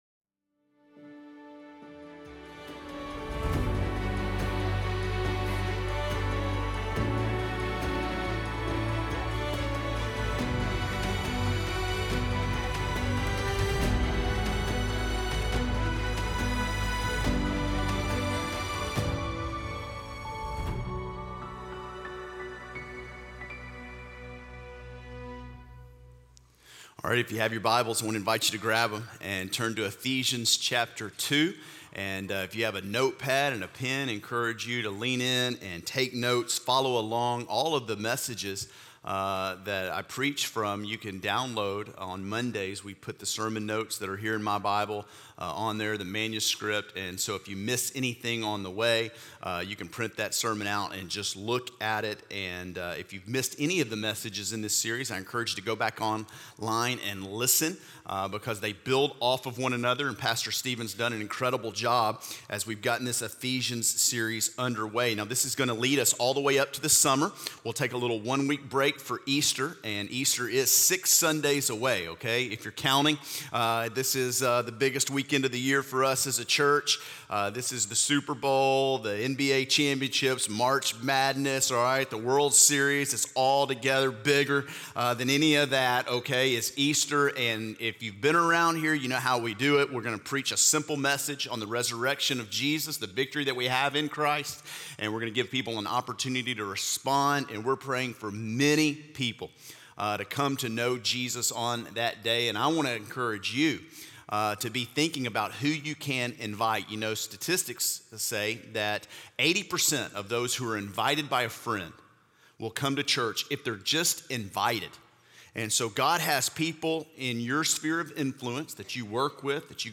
Sunday Sermons – Media Player